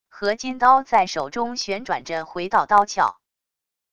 合金刀在手中旋转着回到刀鞘wav音频